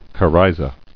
[co·ry·za]